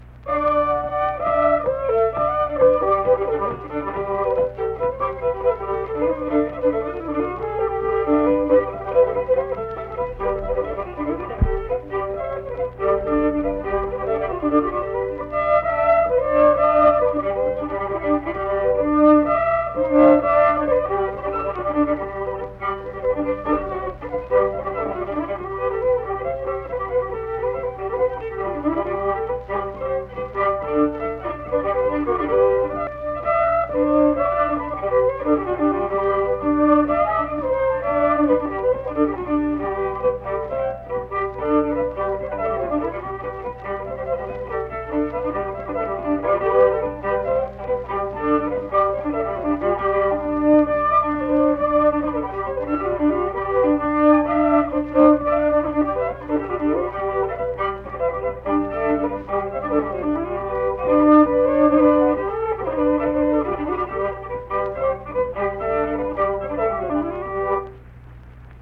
Unaccompanied fiddle music
Instrumental Music
Fiddle
Mingo County (W. Va.), Kirk (W. Va.)